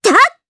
Kirze-Vox_Attack2_jp.wav